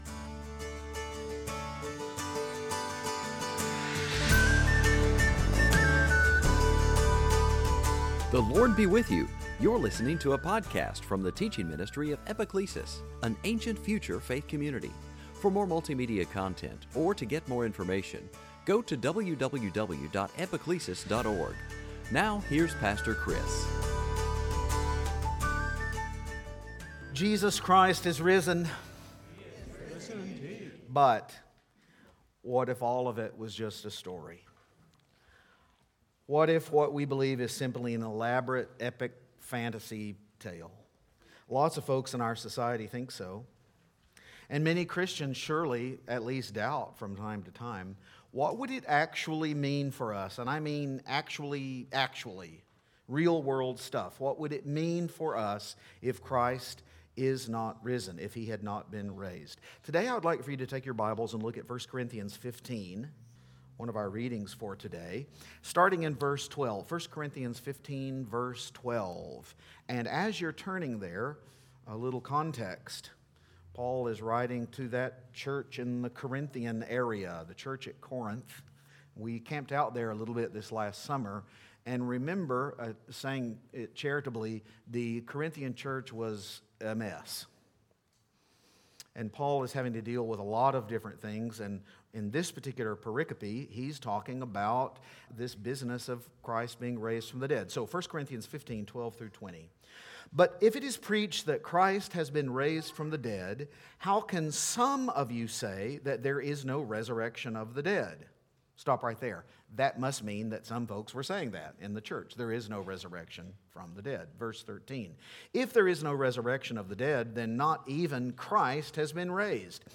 Luke 24:1-5 Service Type: Easter Sunday Jesus Christ is risen.